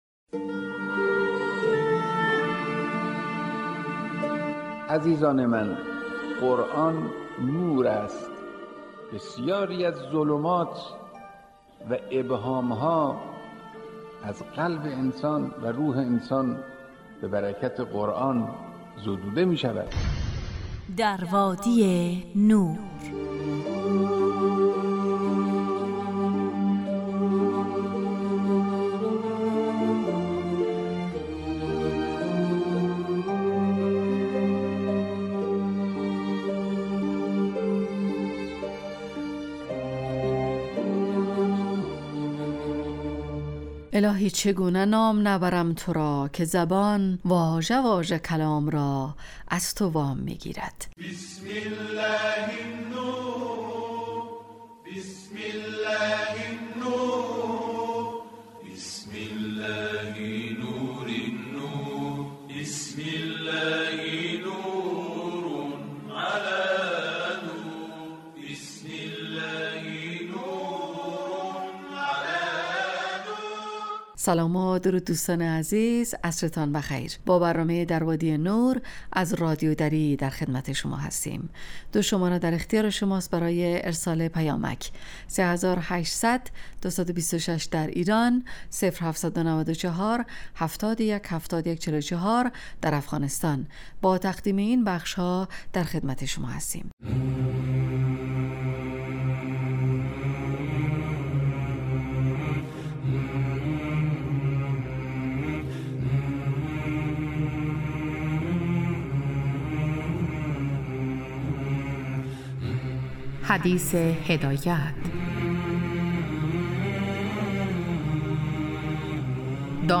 در وادی نور برنامه ای 35 دقیقه ای با موضوعات قرآنی روزهای فرد: ( قرآن و عترت،طلایه داران تلاوت، ایستگاه تلاوت، دانستنیهای قرآنی، تفسیر روان و آموزه های زند...